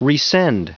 Prononciation audio / Fichier audio de RESEND en anglais
Prononciation du mot : resend